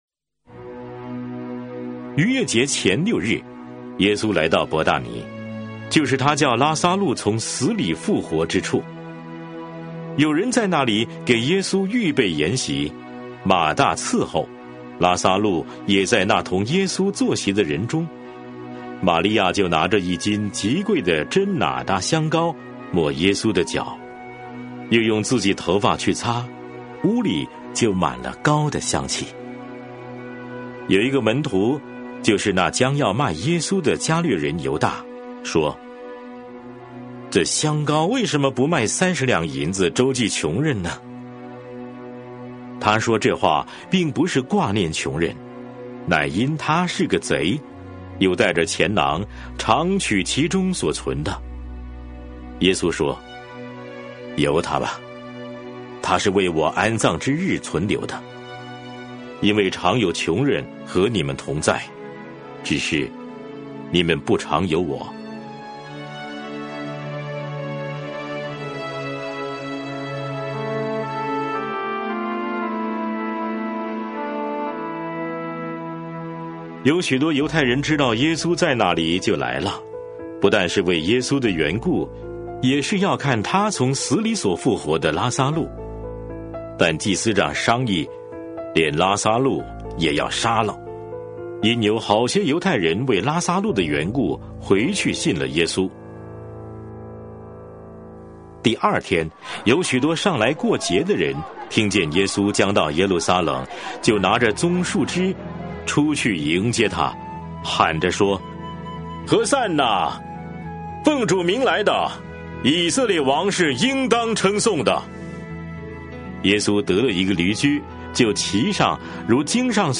每日读经 | 约翰福音12章